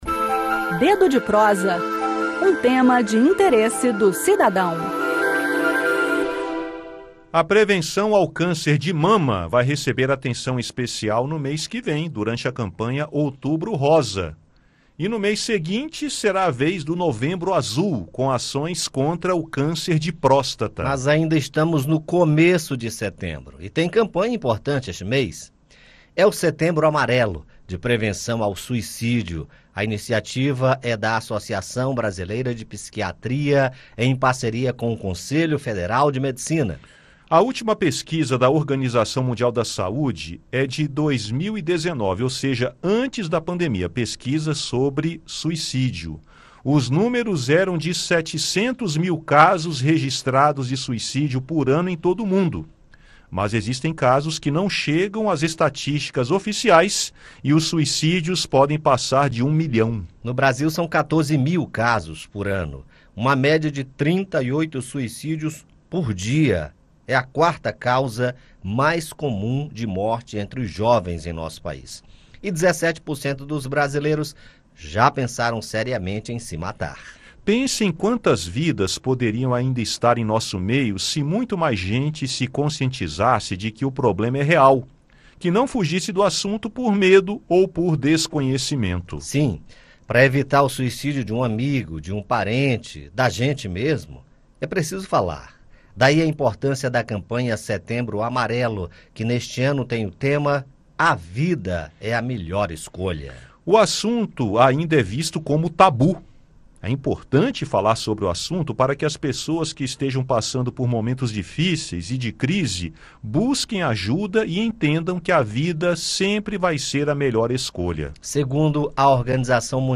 No bate-papo